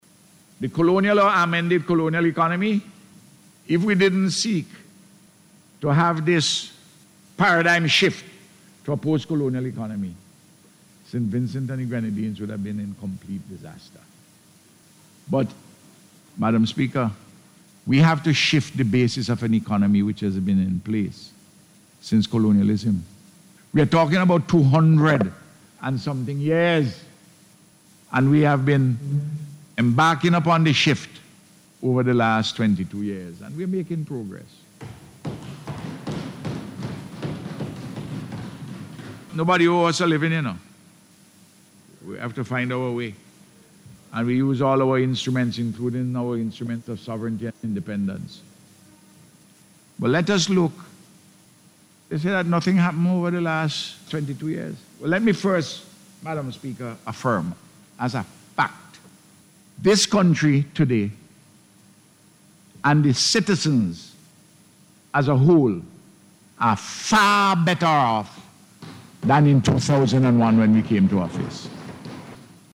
The Prime Minister was making his contribution to the Budget Debate in Parliament yesterday.